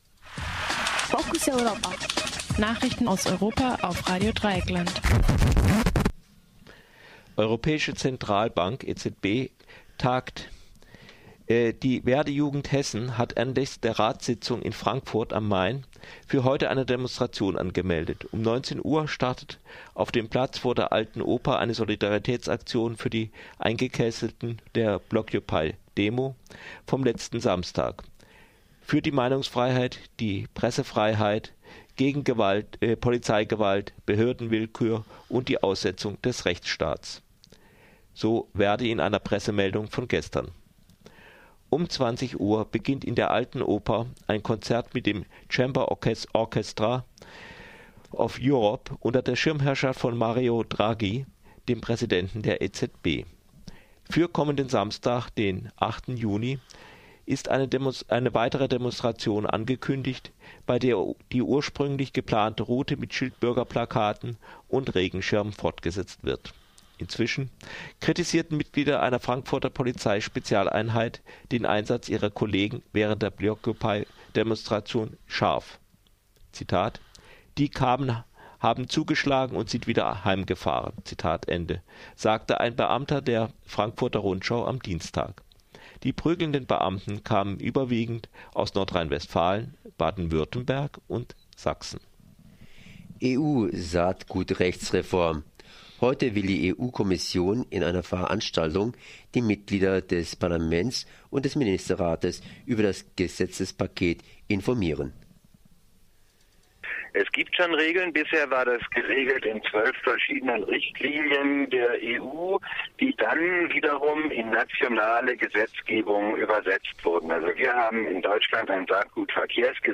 Focus Europa Nachrichten 6. 6. 2013 um 9:30 Uhr